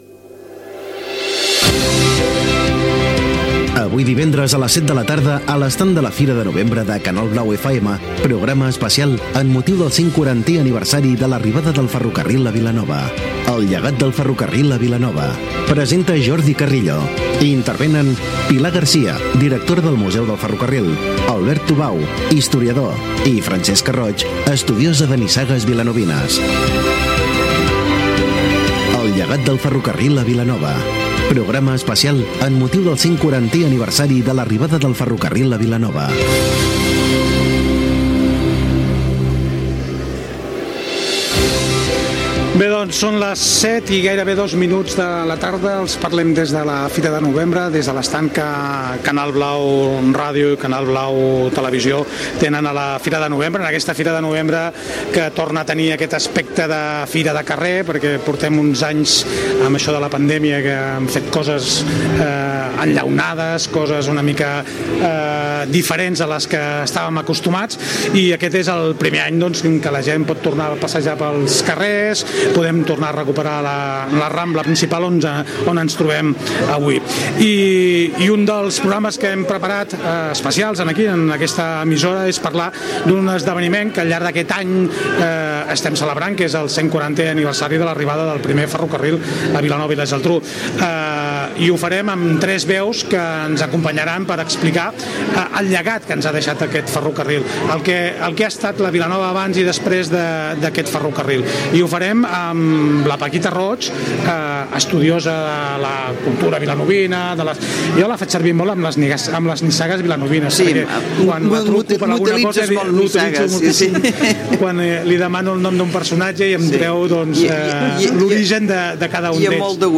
Des de la Fira de Novembre de Vilanova i la Geltrú, programa especial amb motiu del 140 aniversari de l'arribada del ferrocarril a la localitat.
Divulgació